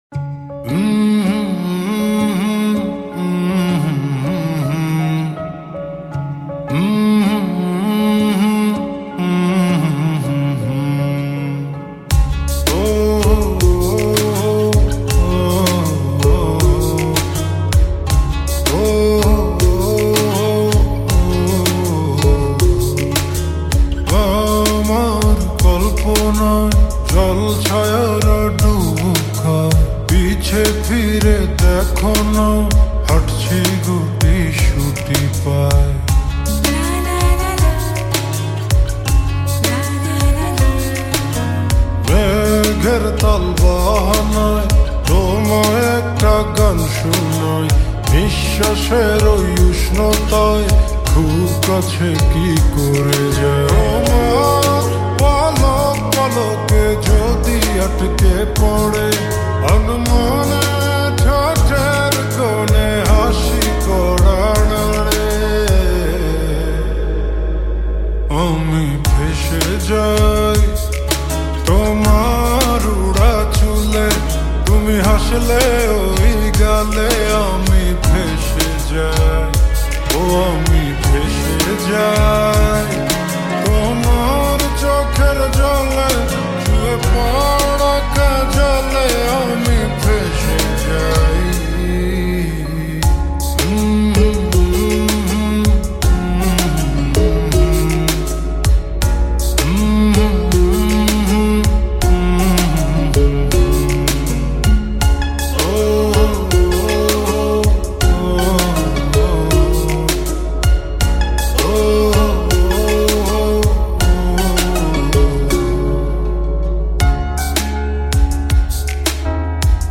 Slowed And Reverb Bangla New Lofi Song